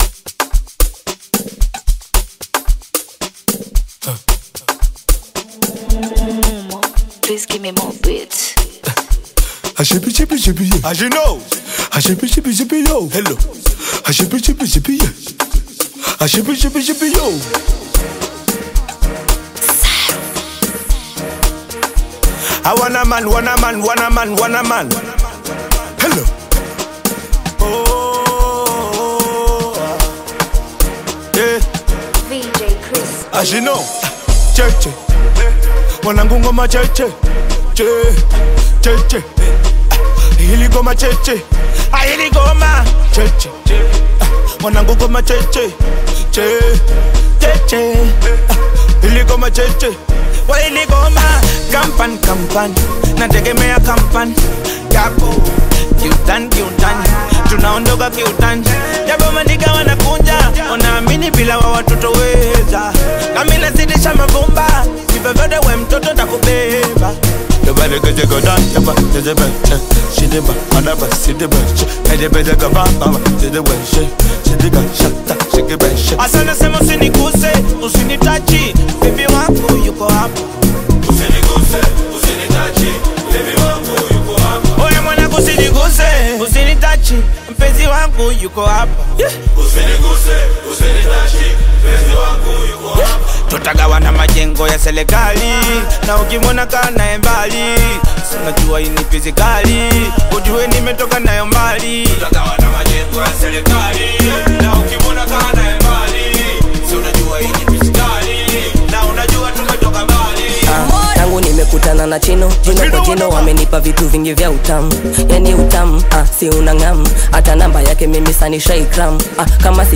Genre: Amapiano